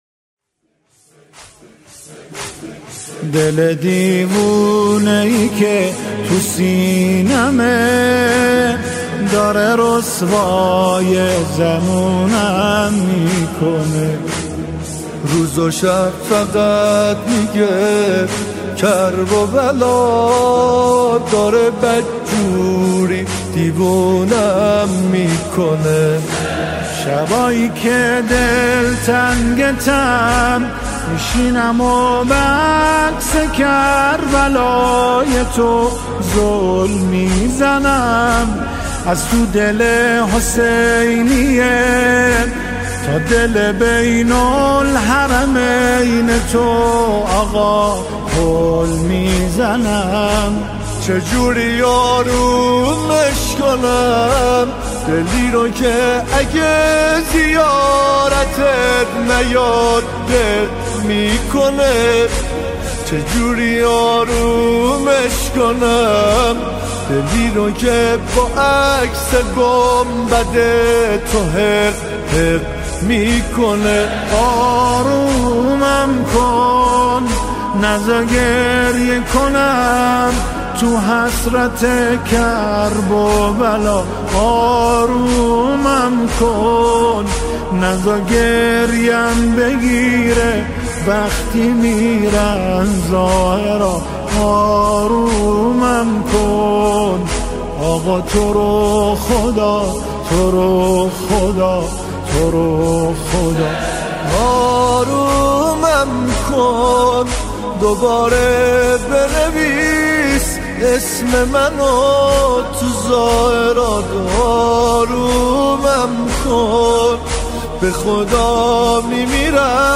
سرودهای امام حسین علیه السلام
همخوانی شعری به مناسبت “ماه محرم”